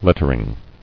[let·ter·ing]